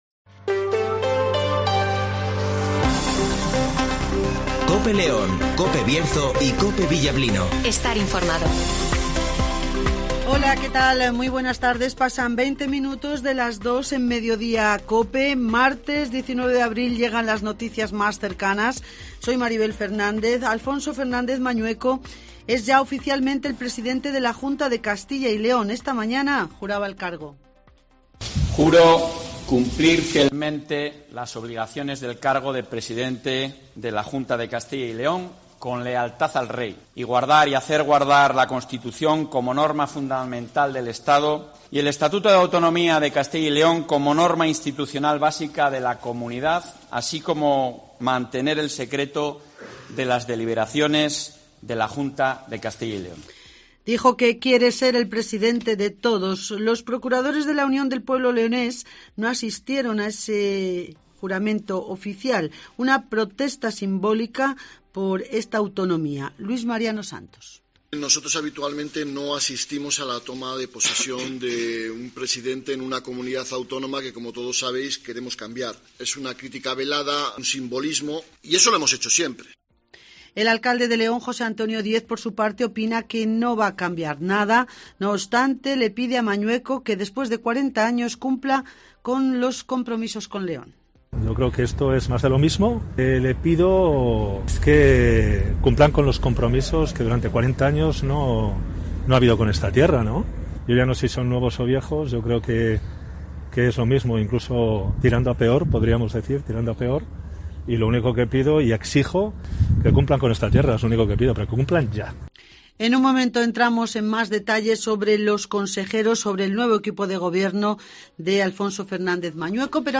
José Antonio Díez ( Alcalde de León )